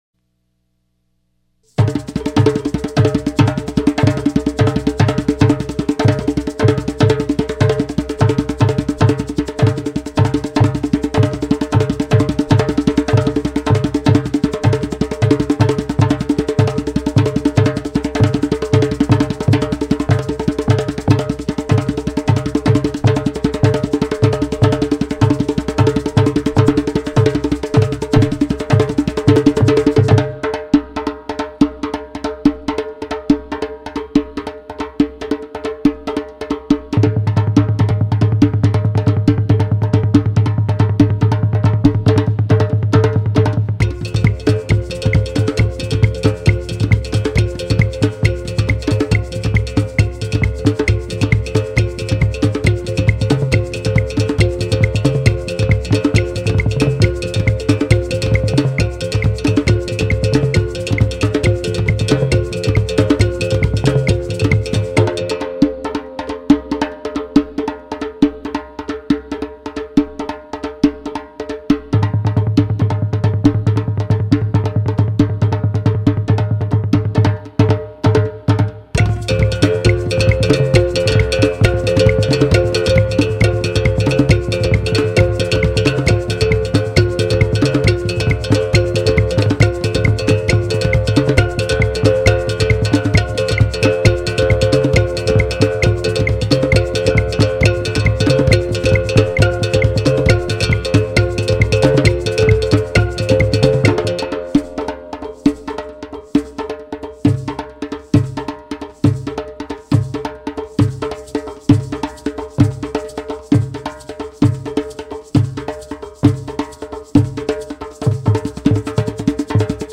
one-man percussion ensemble